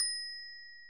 snd_ui_ding.wav